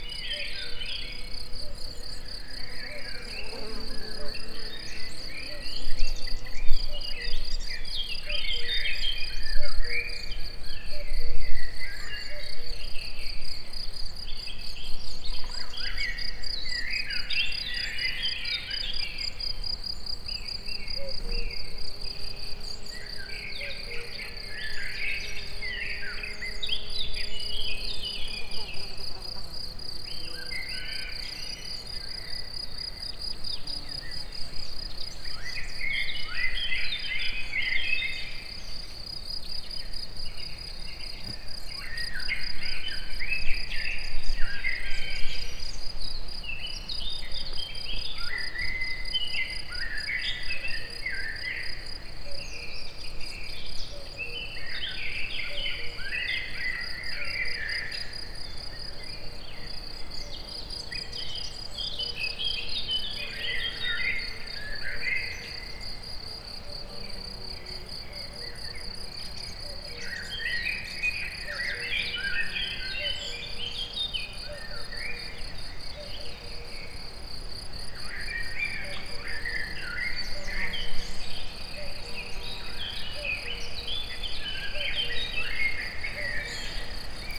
Directory Listing of /_MP3/allathangok/termeszetben/rovarok_premium/
halkabbes4_hangoskornyezet_egerturistahaz01.27.WAV